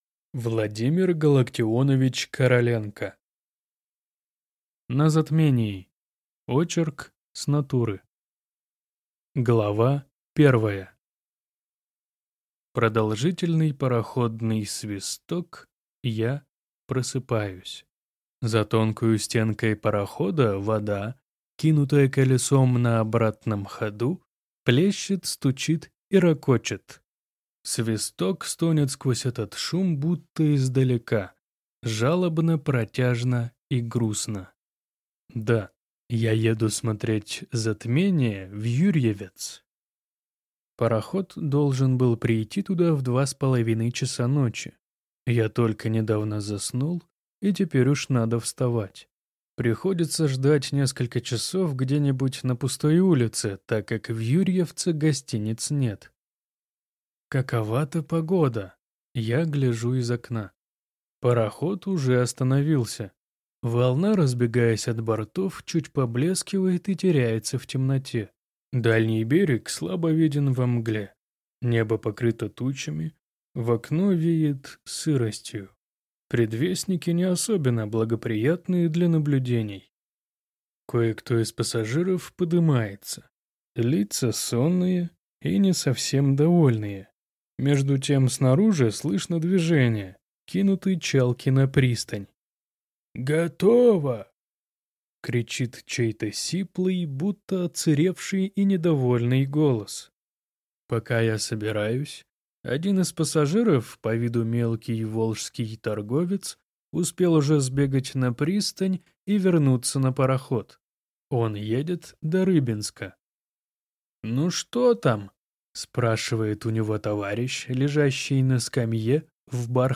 Aудиокнига На затмении